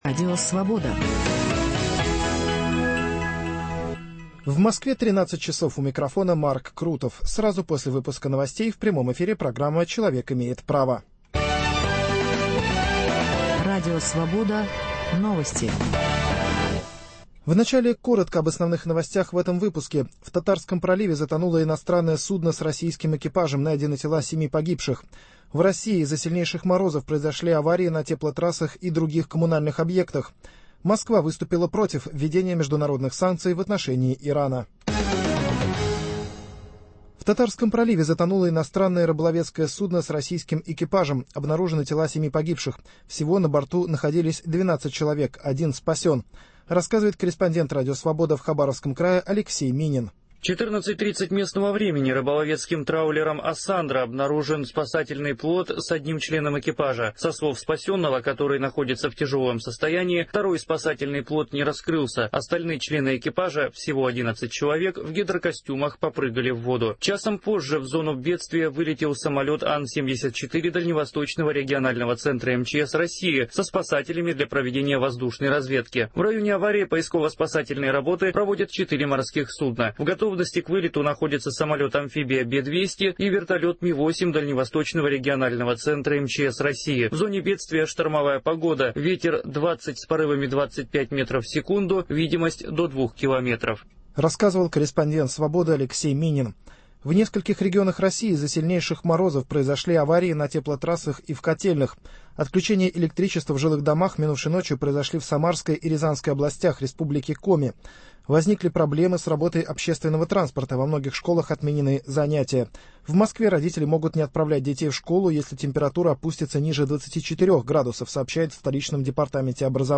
Утром в газете, а с часу дня в прямом эфире - обсуждение самых заметных публикации российской и зарубежной печати. Их авторы и герои - вместе со слушателями.